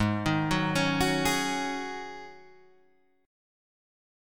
G#7sus4 chord